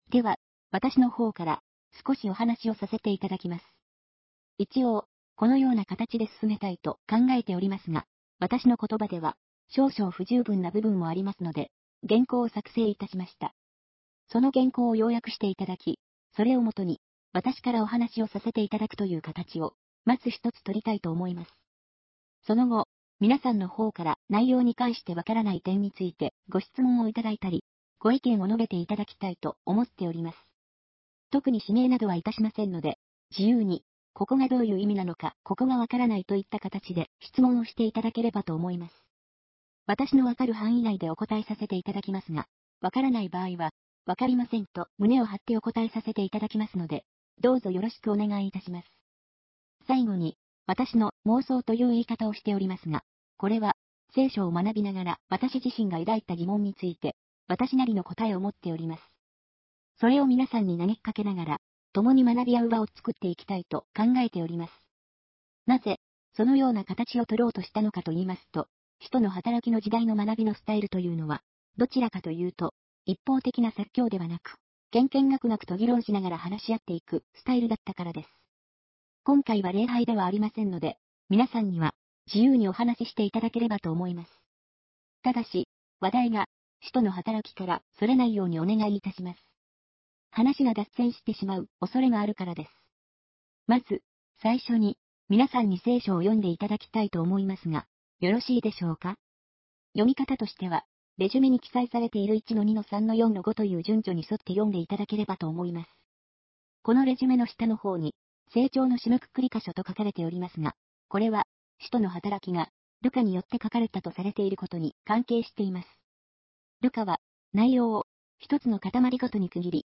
ただし女声になっております。